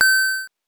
coin_4.wav